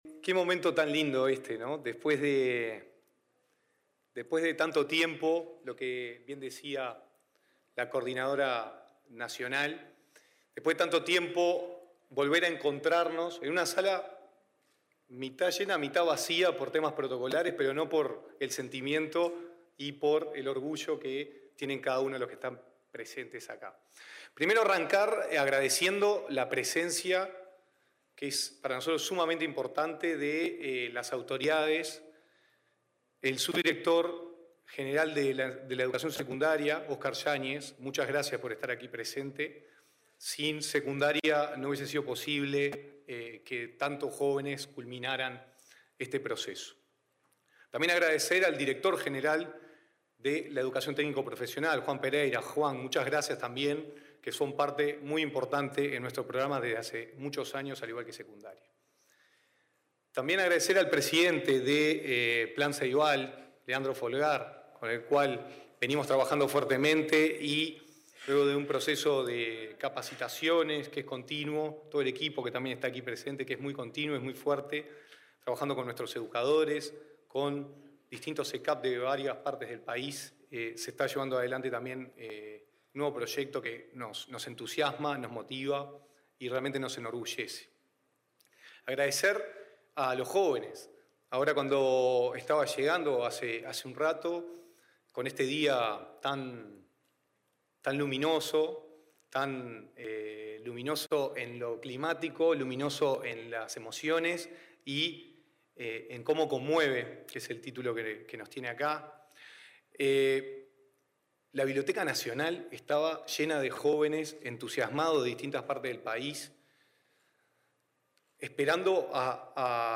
Palabras del director de Educación, Gonzalo Baroni
El director de Educación, Gonzalo Baroni, participó este lunes 8 en una muestra del trabajo de estudiantes de los centros educativos de capacitación y